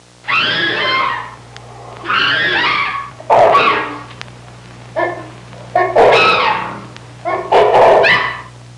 Chimp And Orangutan Sound Effect
chimp-and-orangutan.mp3